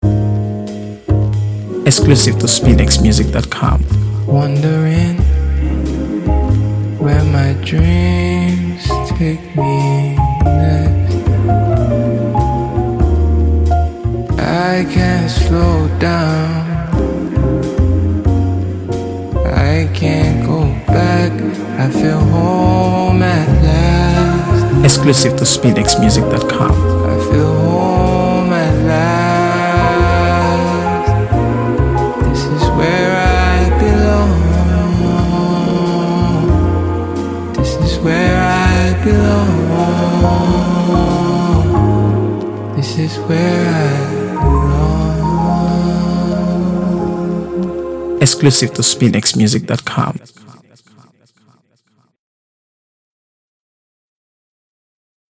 AfroBeats | AfroBeats songs
a smooth, emotive record